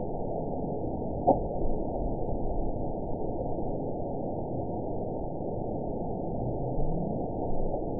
event 911175 date 02/13/22 time 09:53:36 GMT (3 years, 3 months ago) score 9.34 location TSS-AB01 detected by nrw target species NRW annotations +NRW Spectrogram: Frequency (kHz) vs. Time (s) audio not available .wav